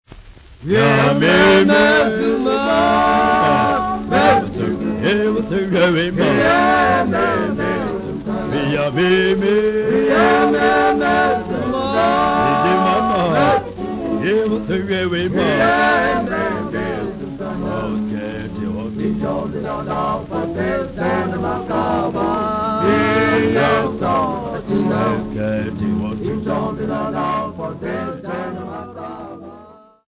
Popular music--Africa
Field recordings
sound recording-musical
Indigenous folk song accompanied
96000Hz 24Bit Stereo